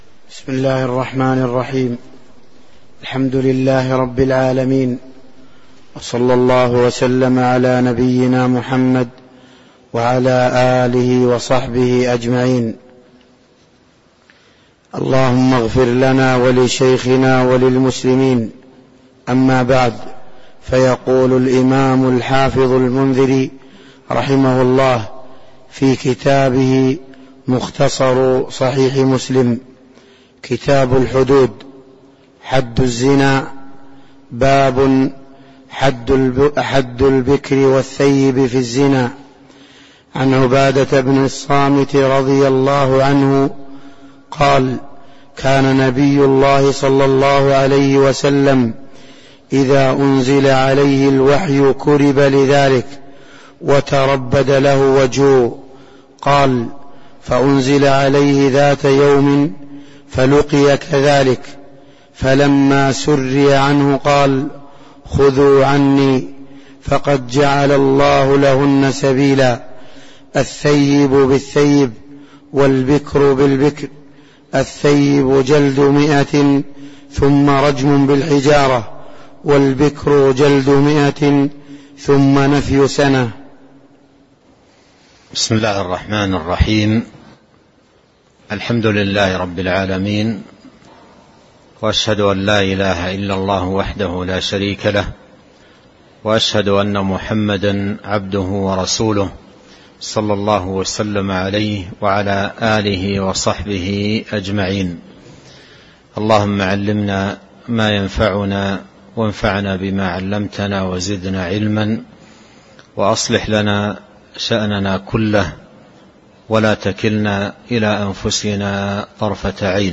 تاريخ النشر ٦ ربيع الأول ١٤٤٣ هـ المكان: المسجد النبوي الشيخ